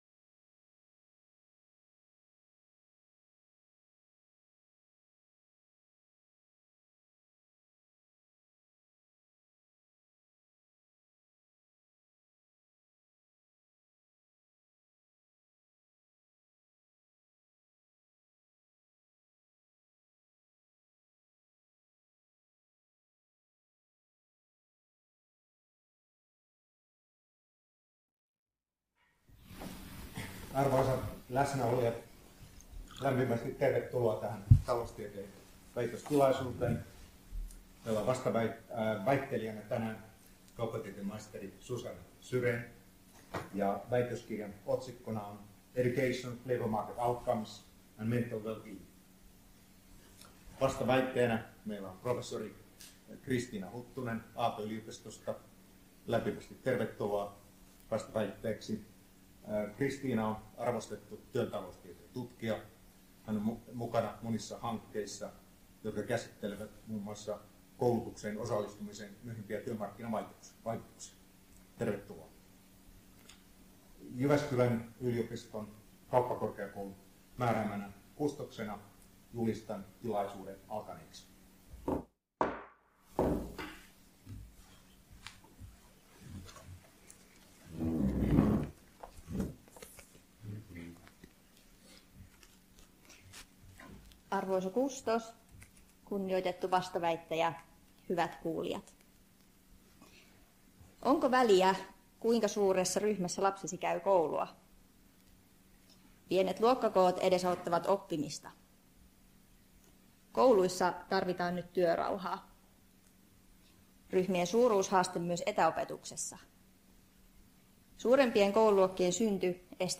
Taloustieteen väitöstilaisuus